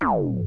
generator_off.wav